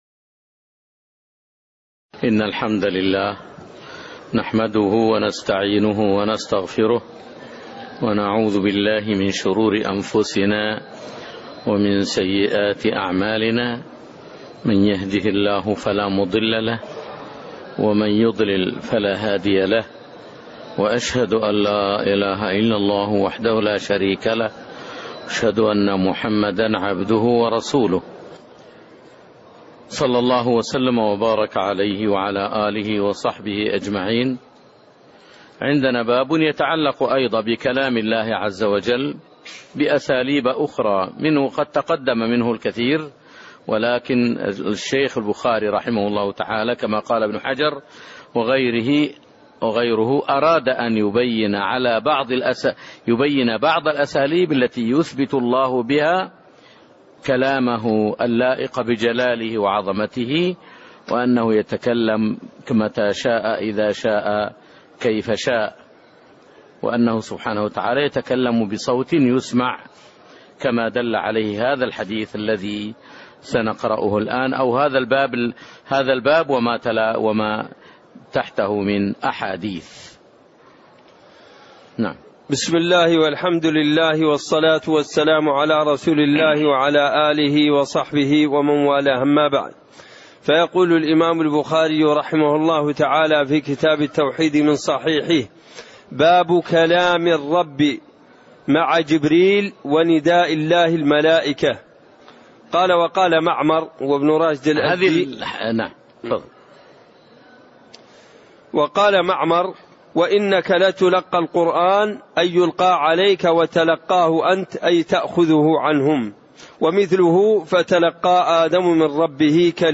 تاريخ النشر ٢٩ ربيع الثاني ١٤٣٥ هـ المكان: المسجد النبوي الشيخ